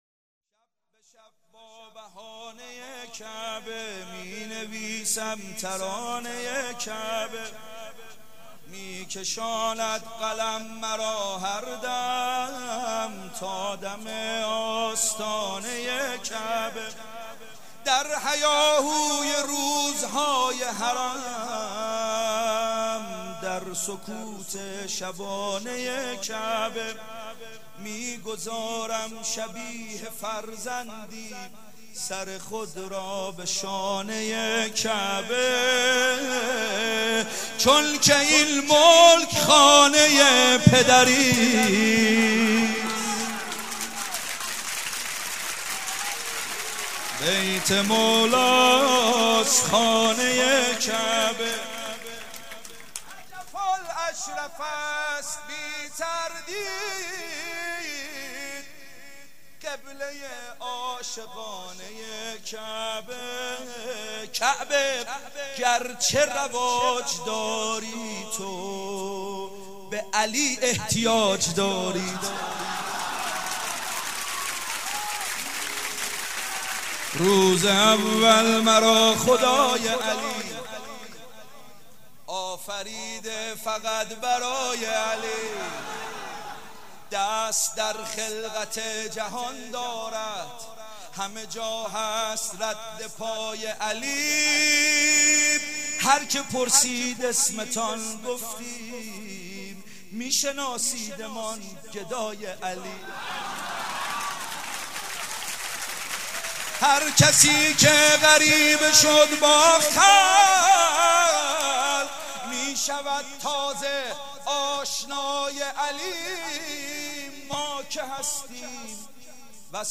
قالب : شعر خوانی